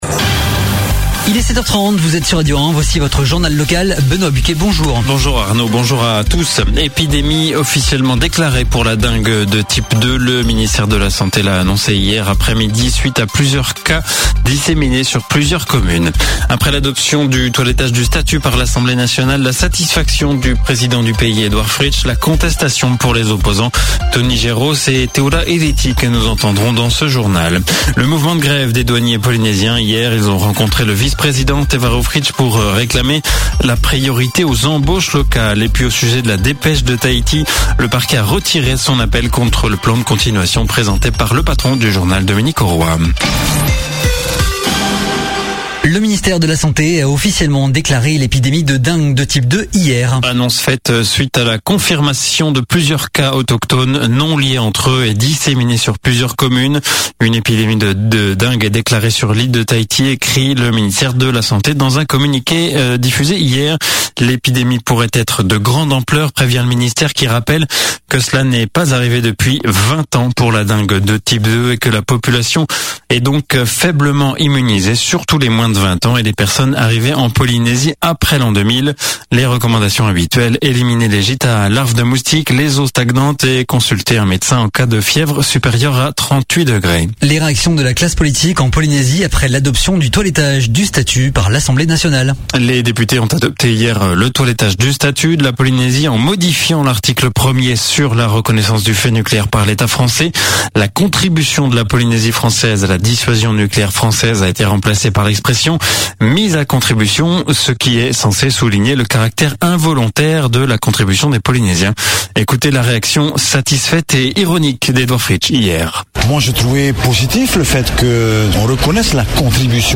Le journal de 7h30, le 12/04/2019